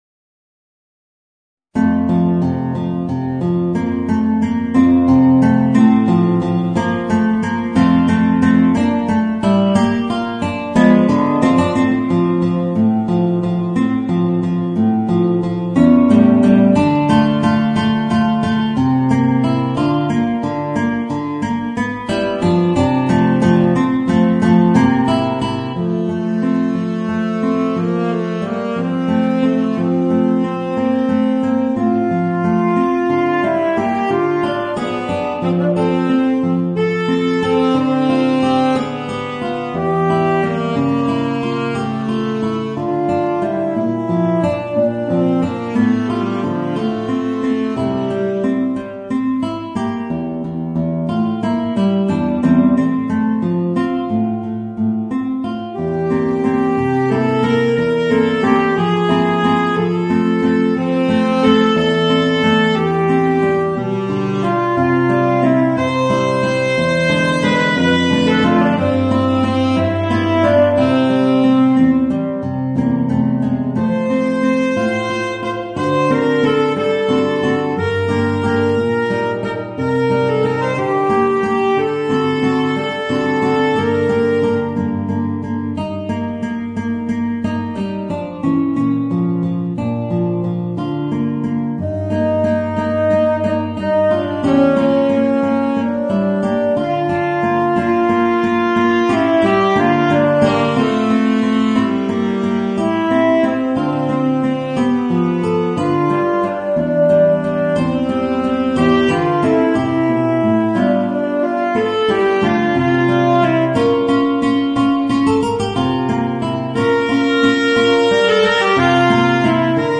Voicing: Guitar and Tenor Saxophone